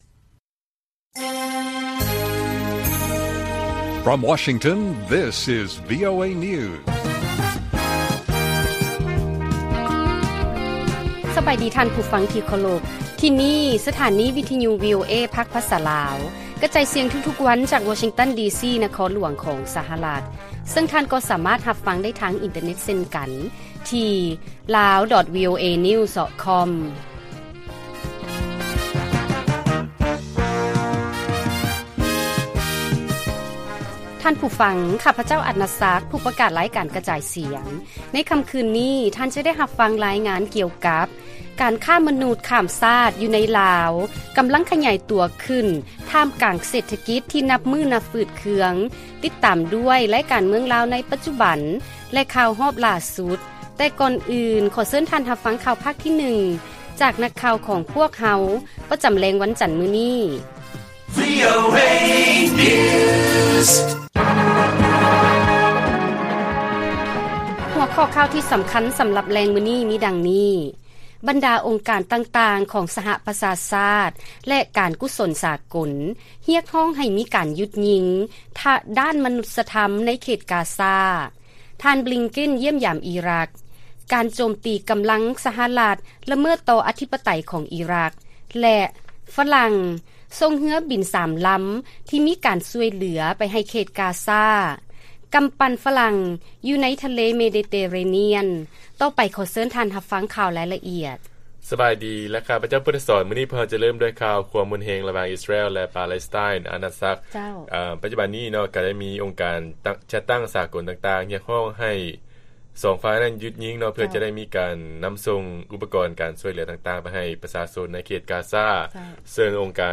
ລາຍການກະຈາຍສຽງຂອງວີໂອເອ ລາວ: ບັນດາອົງການຕ່າງໆຂອງ ສປຊ ແລະ ການກຸສົນສາກົນ ຮຽກຮ້ອງໃຫ້ມີການຢຸດຍິງ ດ້ານມະນຸດສະທຳ ໃນເຂດ ກາຊາ